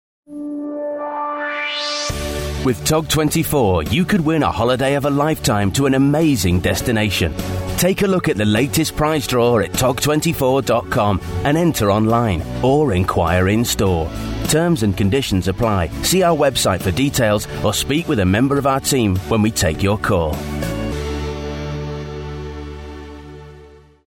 British chap, friendly voice, bright and engaging. Sounds like fun.
on hold/IVR
On hold_sample.mp3